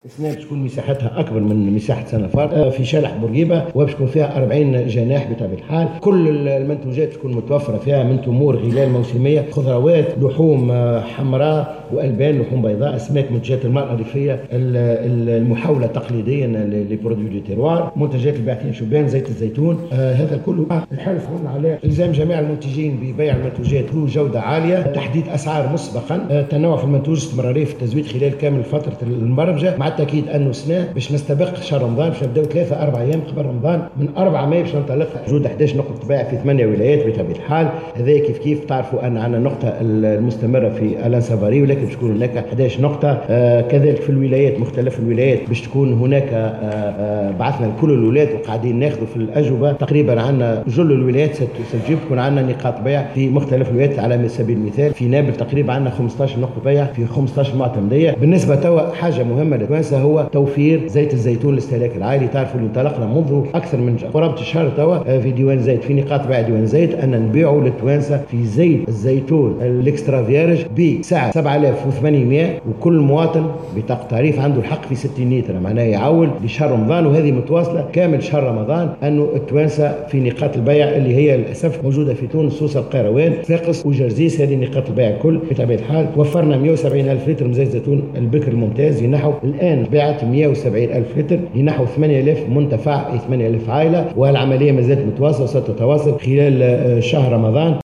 أعلن وزير الفلاحة سمير الطيب، في تصريح لمراسل الجوهرة "اف ام" اليوم الخميس 18 أفريل على هامش ندوة صحفية مشتركة له مع وزير التجار بقصر الحكومة أنه سيتم تركيز خيمة من المنتج إلى المستهلك بشارع الحبيب بورقيبة .